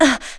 Laudia-Vox_Damage_01.wav